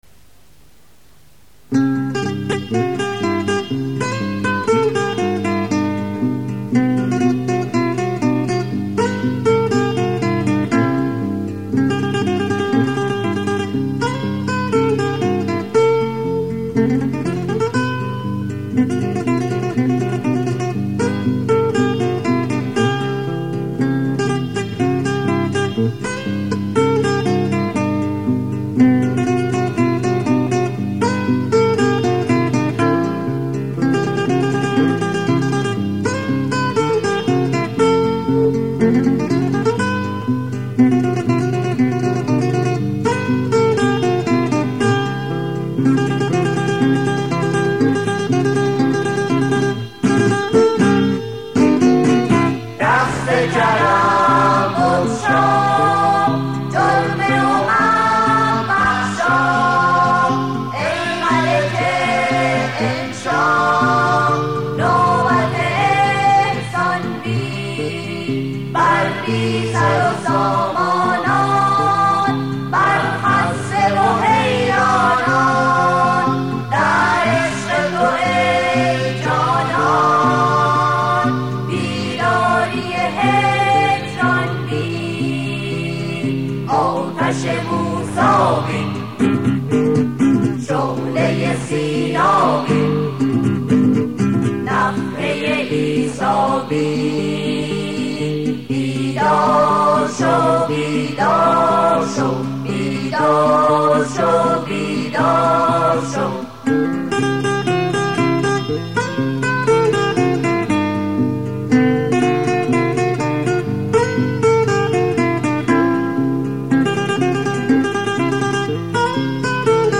سرود - شماره 3 | تعالیم و عقاید آئین بهائی